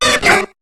Cri de Coxyclaque dans Pokémon HOME.